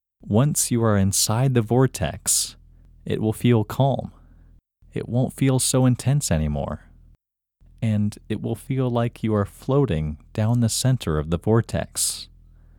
IN – Second Way – English Male 14